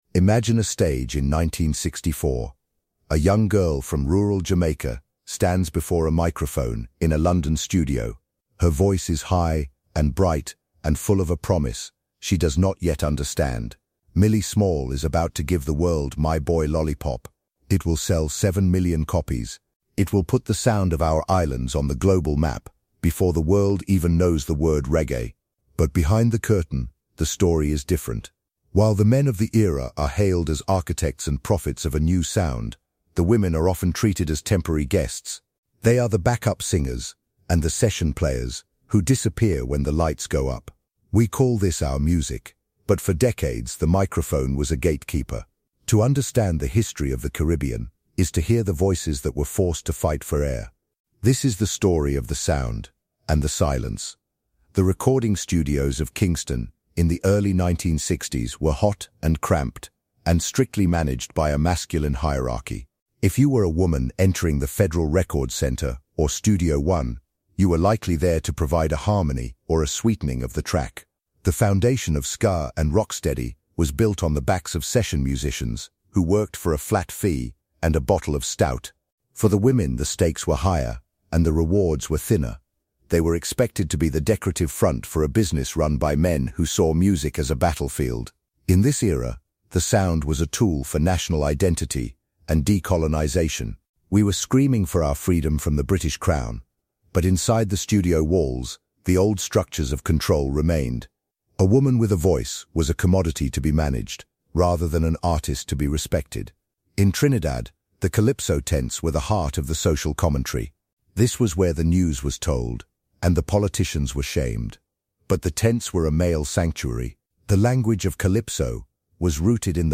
This long-form documentary exploration of THE HISTORY OF THE CARIBBEAN dives into the grit and the silence behind the global stage of Caribbean music.